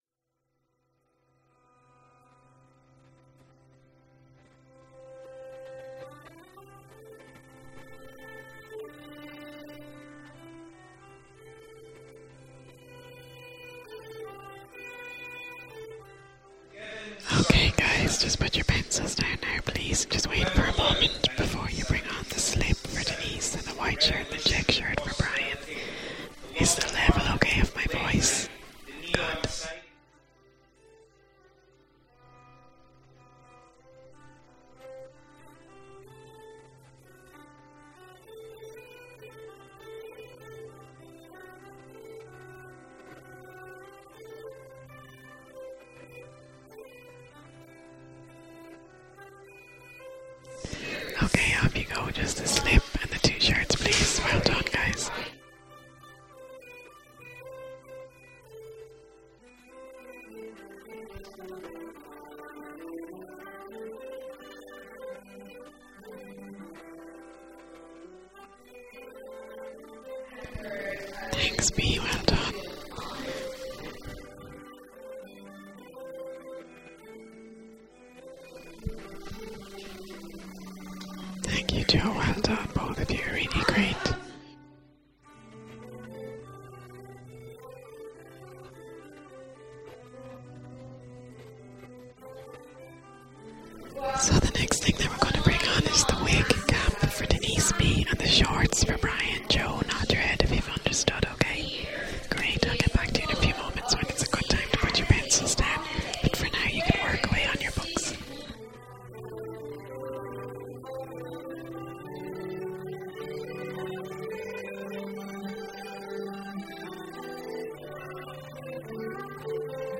A little hint of what the children heard in Adler & Gibb, featuring the brilliant and gentle whispering